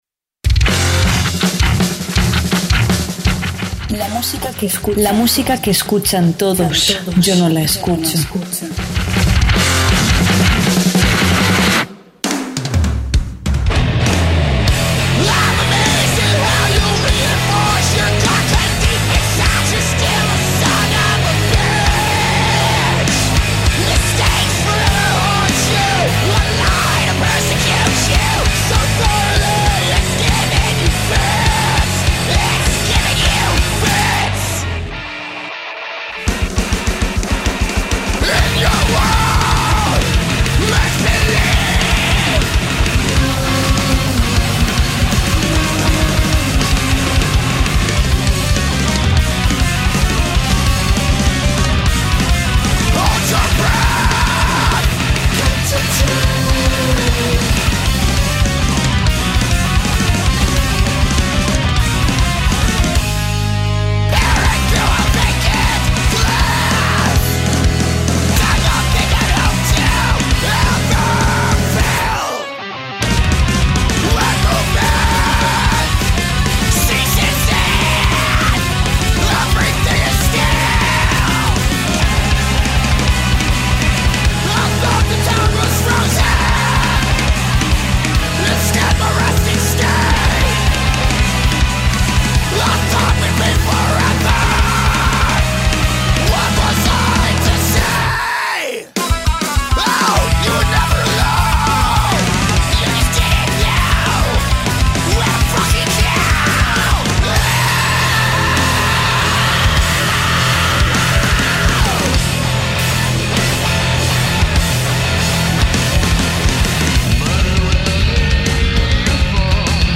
Entrevista a Silvertown | FLACSO Radio
En el programa de hoy te presentamos una entrevista con Silvertown, banda chilena que nos presenta su propuesta musical influenciada por el rock británico.